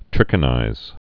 (trĭkə-nīz)